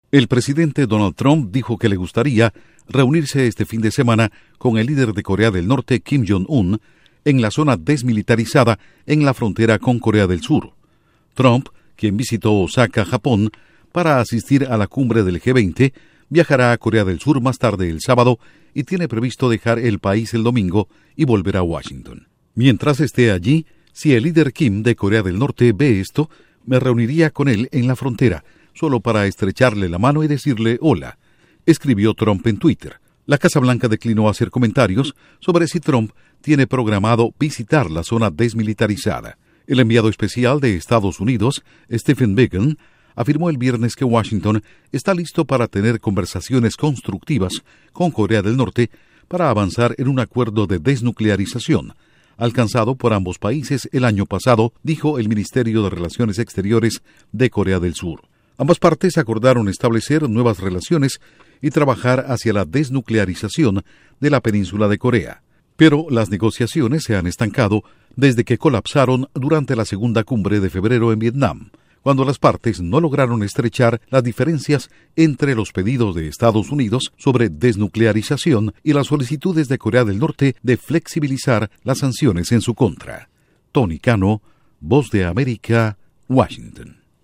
Trump podría reunirse con líder de Corea del Norte en zona desmilitarizada este fin de semana. Informa desde la Voz de América en Washington